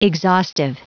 Prononciation du mot exhaustive en anglais (fichier audio)
Prononciation du mot : exhaustive